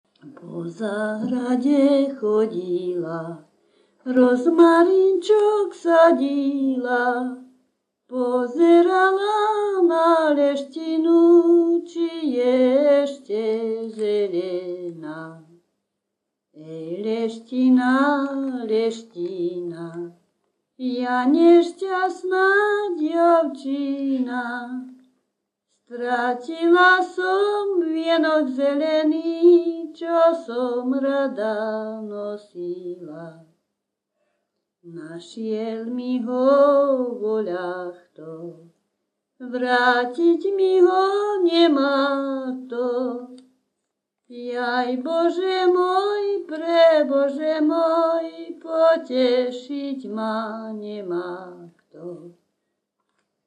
Popis sólo ženský spev bez hudobného sprievodu
Miesto záznamu Litava
Kľúčové slová ľudová pieseň